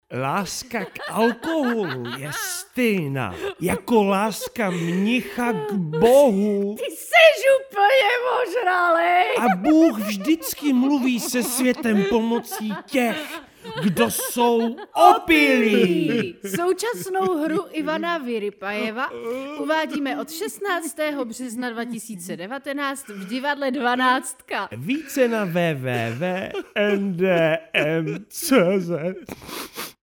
Oficiální audiospot